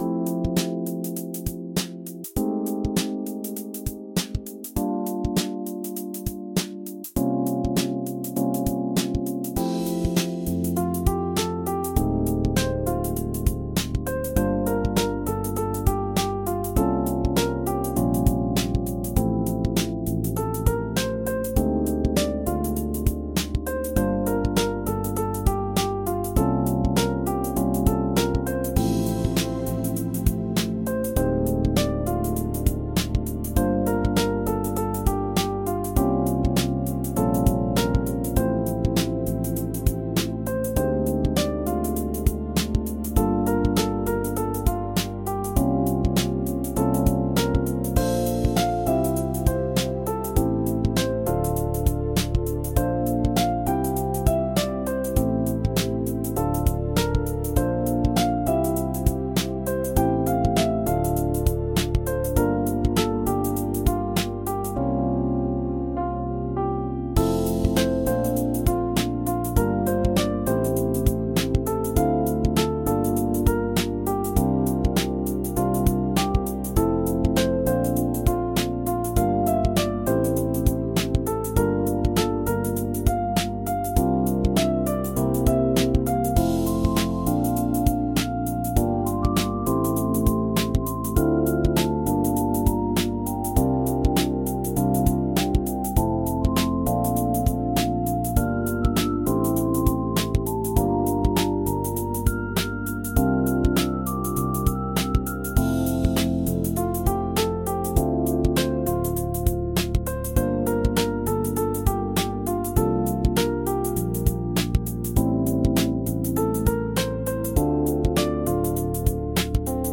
F Major – 100 BPM
Chill
Pop
Romantic